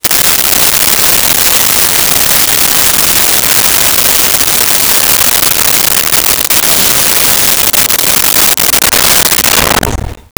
Turn Shower Off 01
Turn Shower Off 01.wav